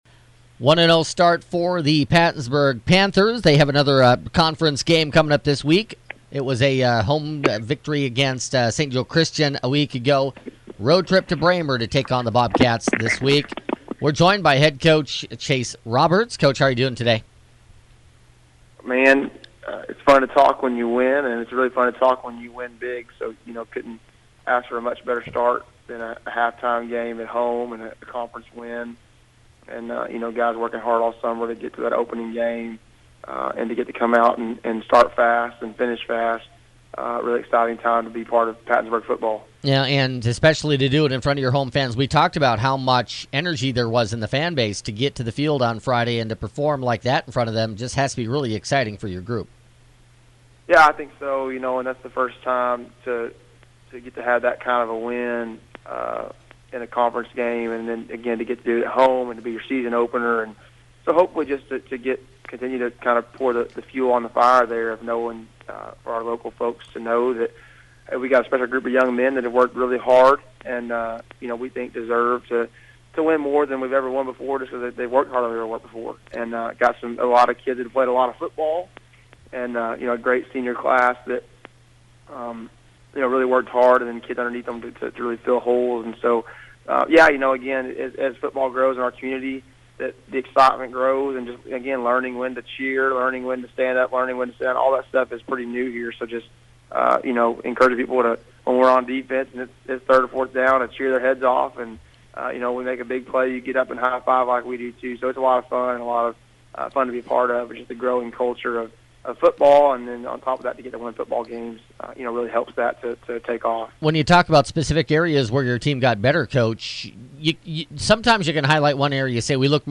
Pattonsburg Panther coaches show for Tuesday, August 28th - KTTN-KGOZ Sports
in an interview